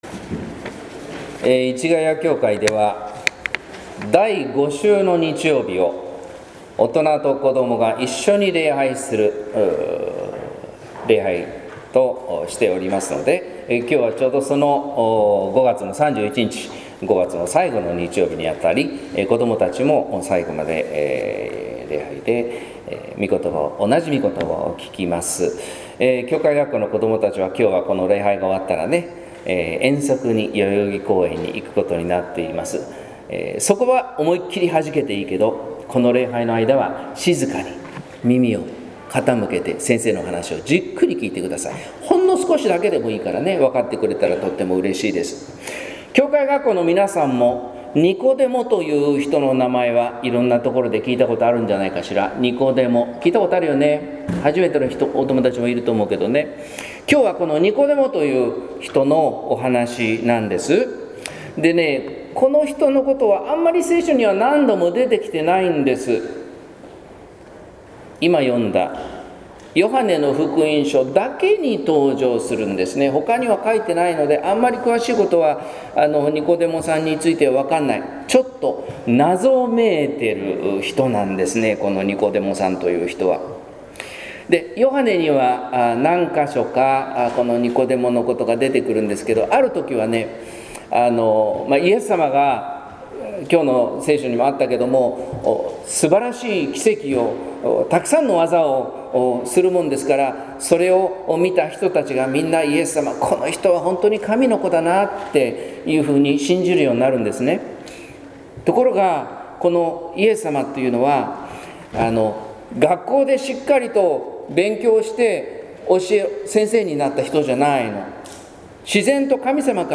説教「天上に聴く」（音声版）